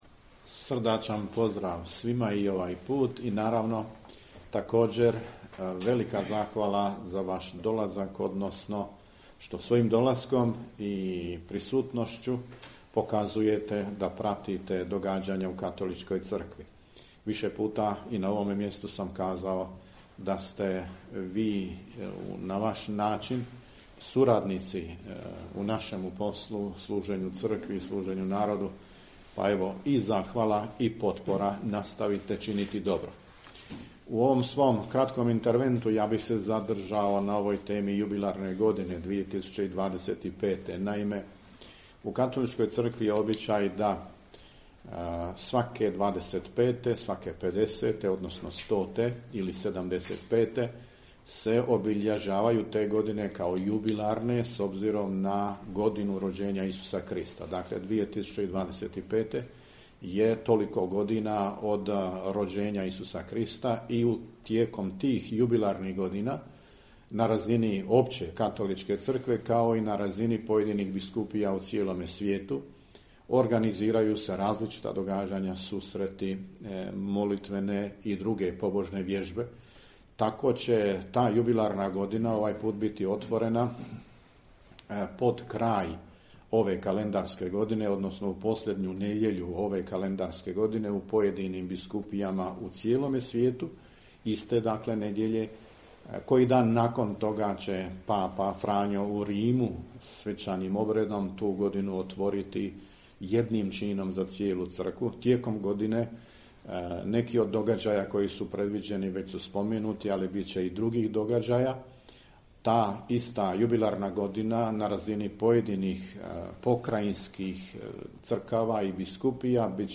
Video: Nadbiskup Vukšić i biskup Palić govorili na tiskovnoj konferenciji o 91. redovitom zasjedanju Biskupske konferencije BiH